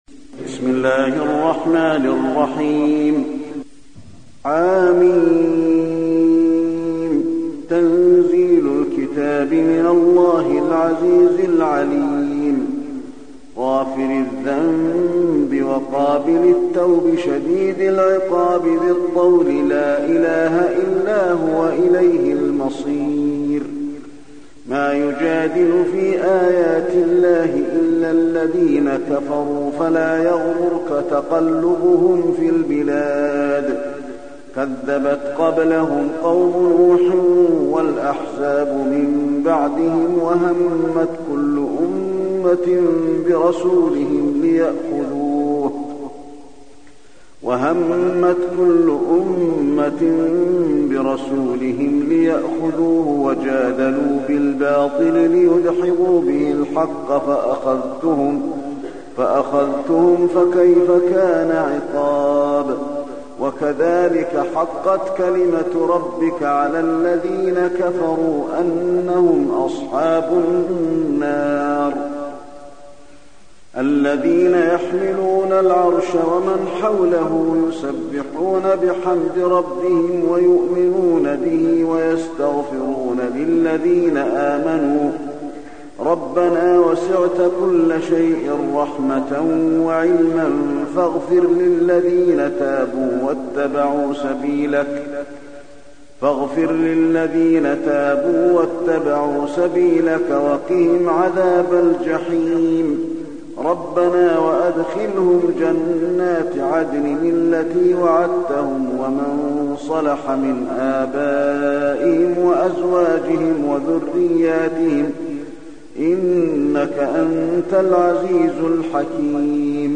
المكان: المسجد النبوي غافر